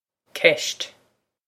ceist cesht
This is an approximate phonetic pronunciation of the phrase.